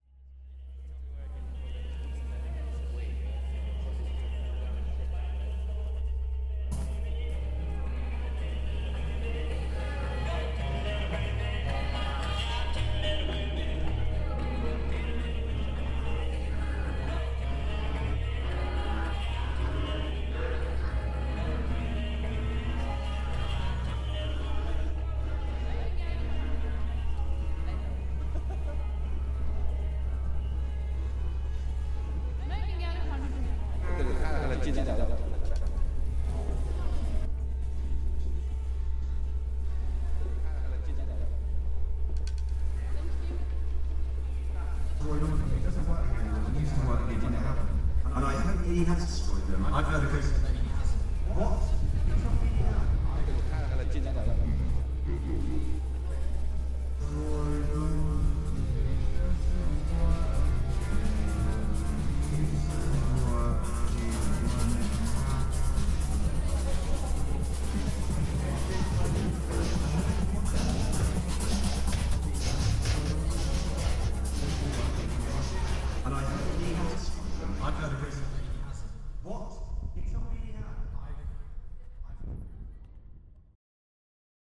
An edited version of the Camden Lock Market walkthrough, possibly emulating how you might feel if you walked through it after a hefty dose of dodgy legal highs bought from one of the stalls.